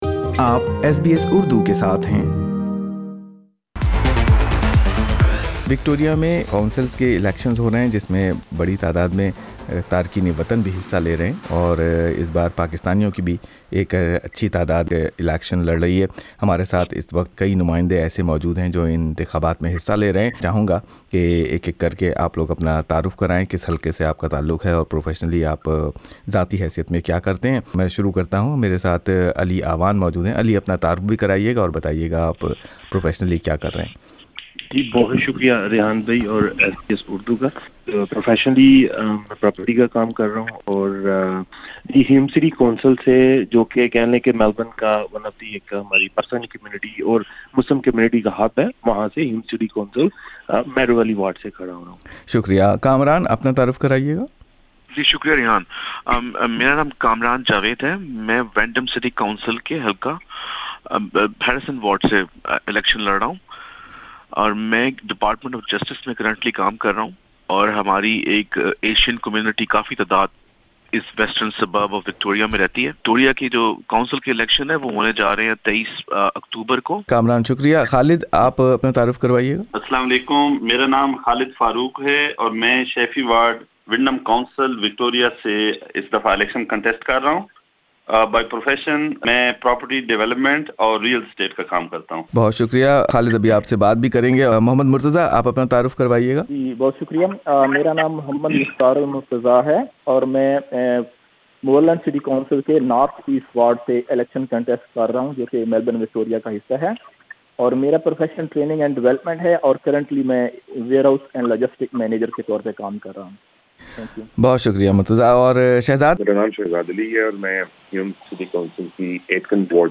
اس وقت ان امیدواروں سے لوگوں کو کیا امیدیں ہیں اور وہ ان کو کیسا پورا کریں گے ایس بی ایس اردو سے بات کرتے ہوئے ان امیدواروں نے بتایا ہے۔